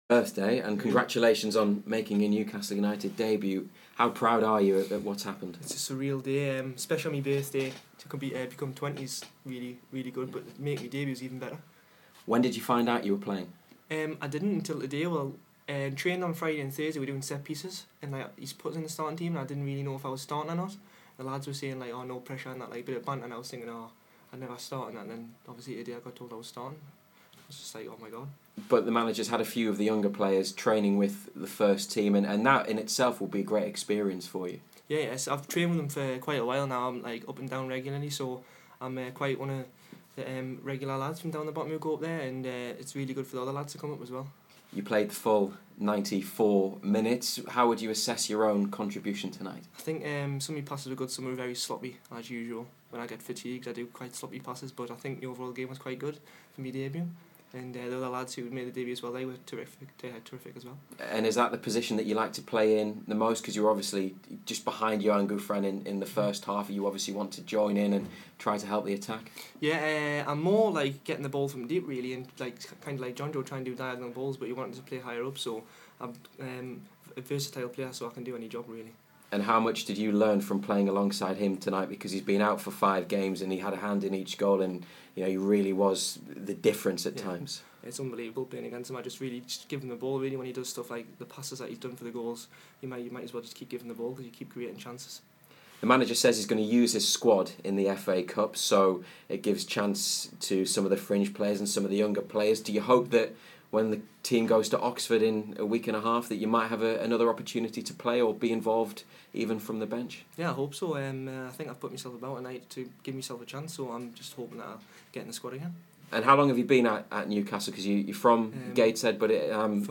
Dan Barlaser chats to BBC Newcastle after making his senior debut in tonight's 3-1 win against Birmingham City in the Emirates FA Cup.